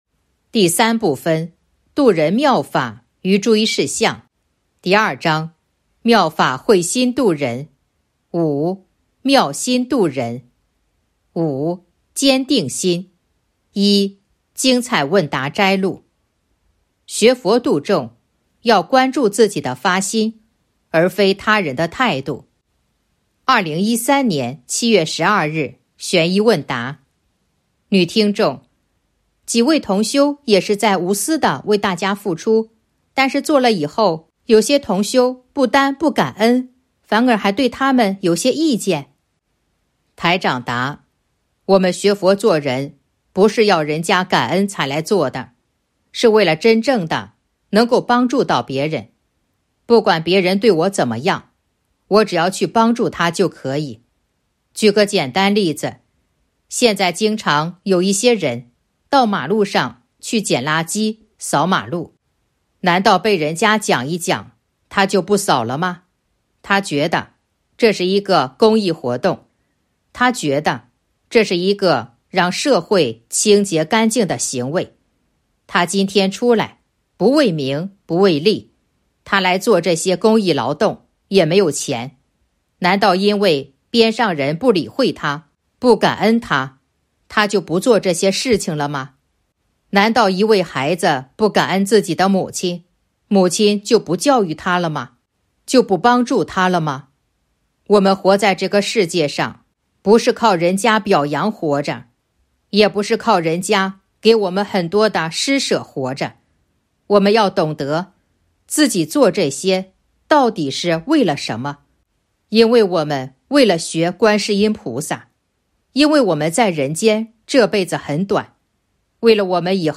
049.（五）坚定心 1. 精彩问答摘录《弘法度人手册》【有声书】